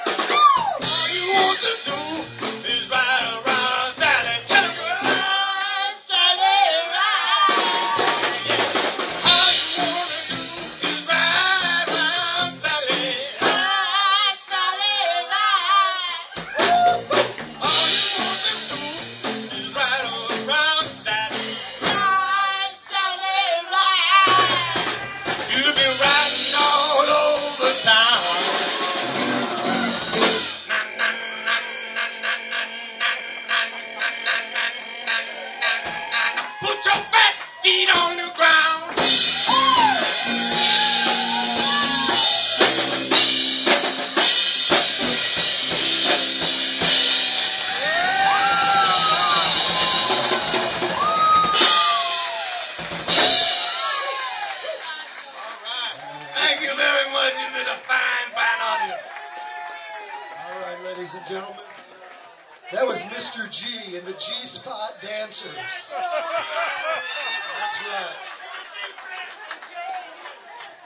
5th OTS Recital - Winter 2005 - rjt_4224